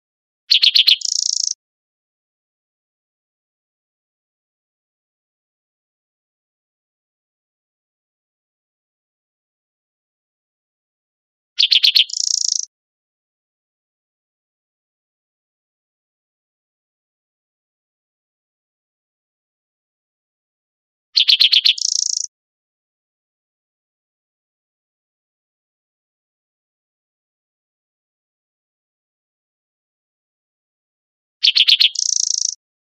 Spotted Towhee | Ask A Biologist
Often found scratching noisily in leaf litter in dense shrubbery, chaparral and forest edges, the Spotted Towhee will sometimes come out in the open and sing from the top of an exposed bush.